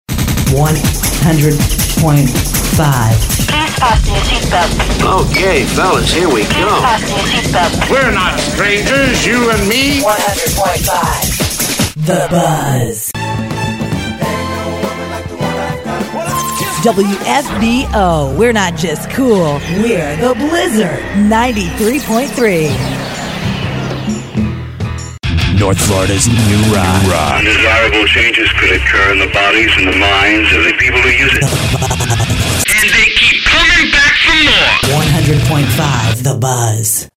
Voice Imaging Demos
FinalImagingDemo.mp3